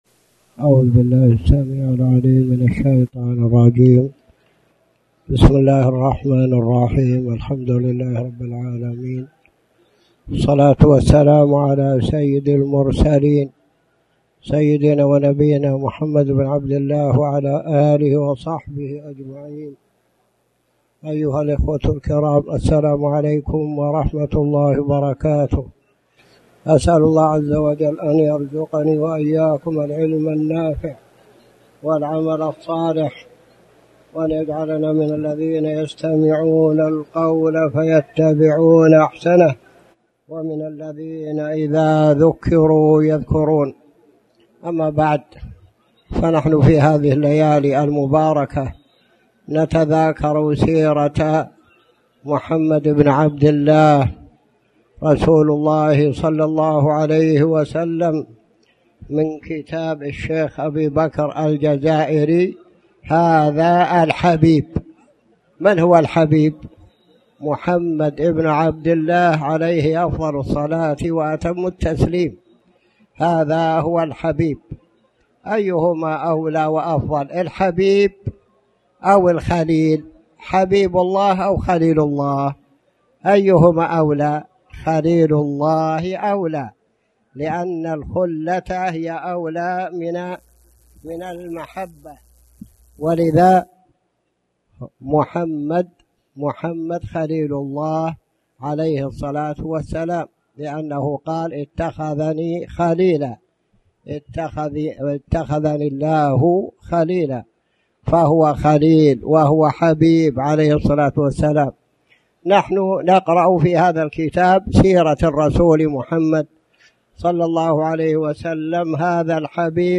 تاريخ النشر ٥ محرم ١٤٣٩ هـ المكان: المسجد الحرام الشيخ